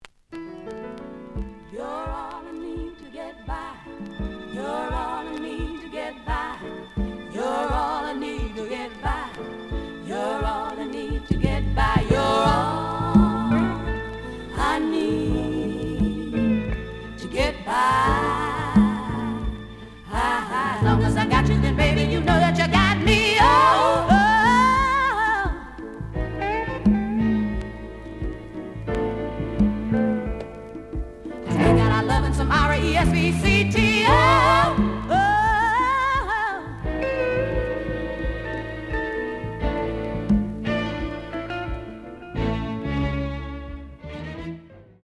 The audio sample is recorded from the actual item.
●Genre: Soul, 60's Soul
●Record Grading: VG~VG+ (傷はあるが、プレイはおおむね良好。Plays good.)